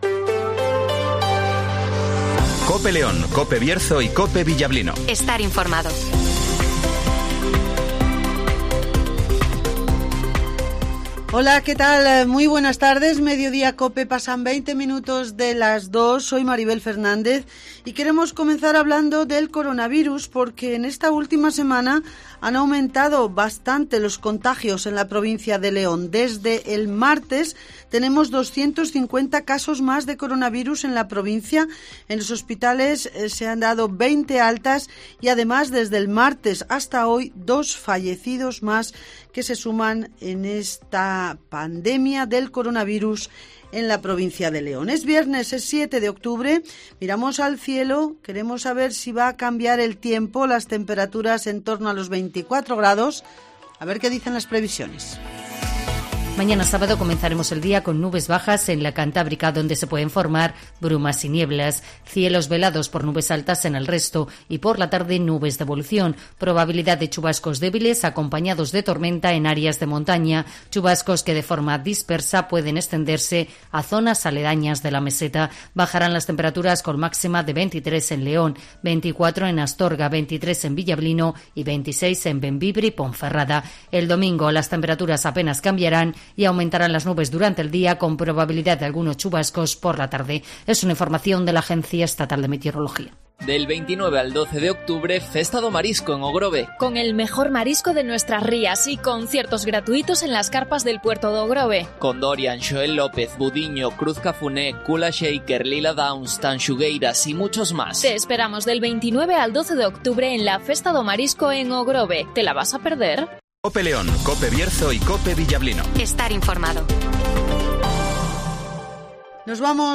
- Antonio Silván ( Senador del PP )
- José Luís Rodríguez Zapatero ( Ex-Presidente del Gobierno - PSOE )